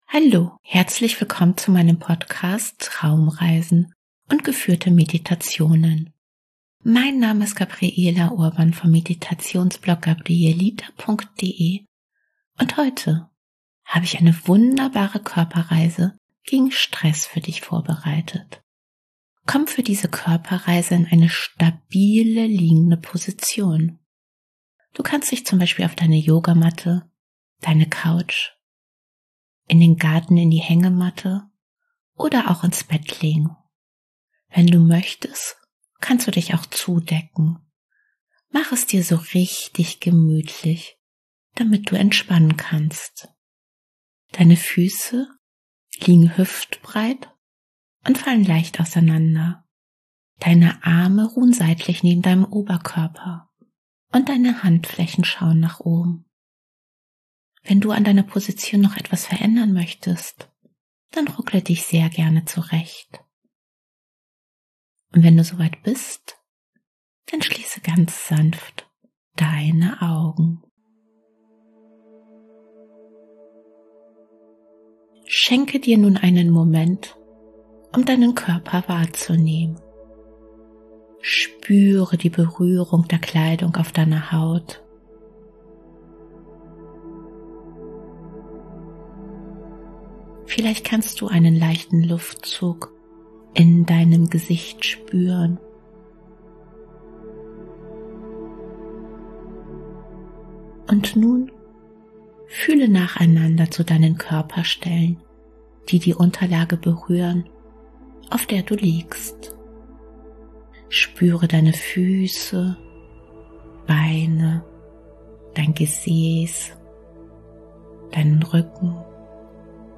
#063: Geführte Körperreise gegen Stress